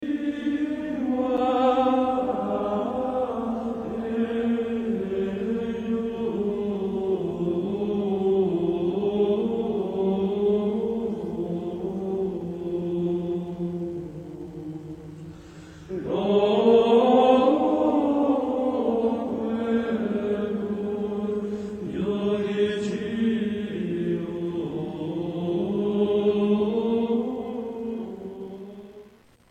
Tags: Gregorian Chants Choir Religion Church music